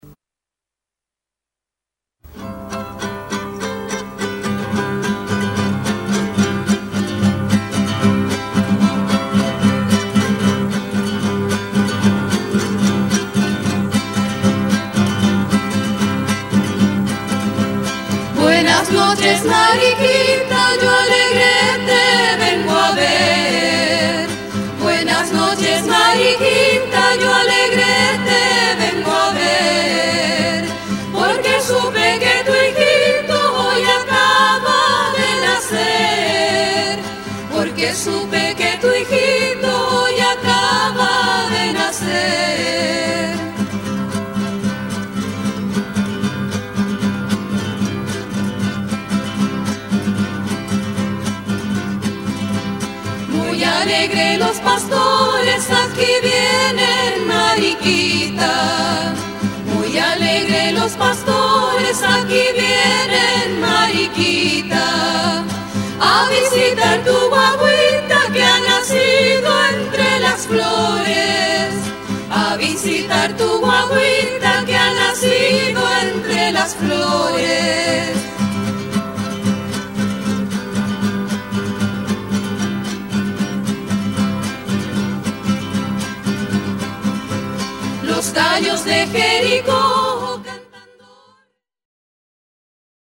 Canto a lo divino "Bendita sea tu pureza", en honor a la Virgen María acompañado por guitarrón chileno